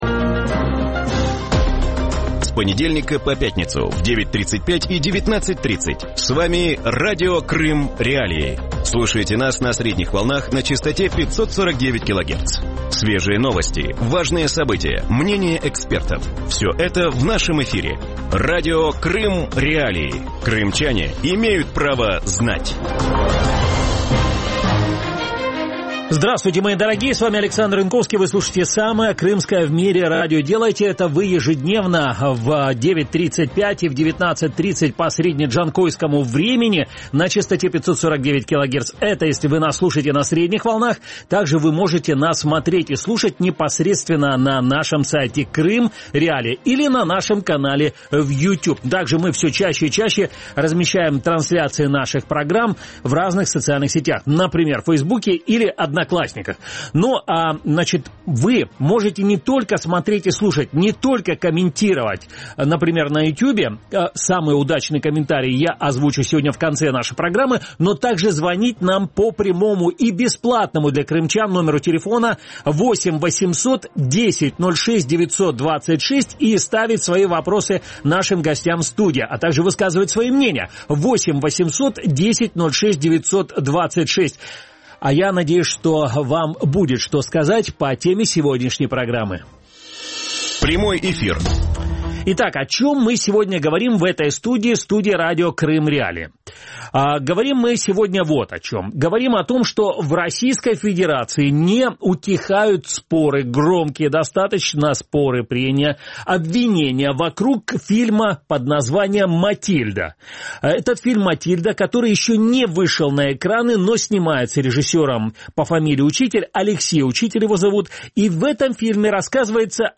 В вечернем эфире Радио Крым.Реалии говорят о настойчивом желании экс-прокурора аннексированного Крыма, депутата Государственной думы Натальи Поклонской запретить к показу фильм Алексея Учителя «Матильда» о жизни императора Николая II. Что стоит за рвением отдельных российских депутатов не допустить «фальсификацию истории» и как государственная политика в России влияет на сферу культуры?